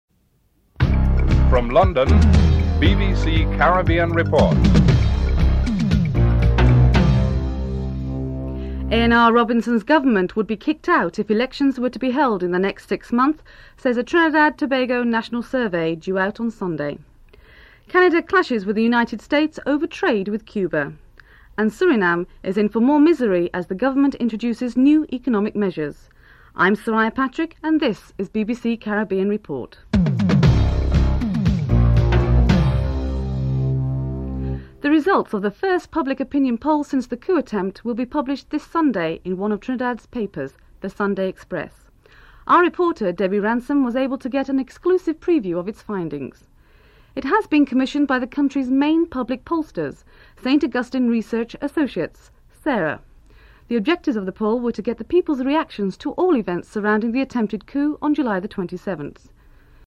1. Headlines (00:00-00:33)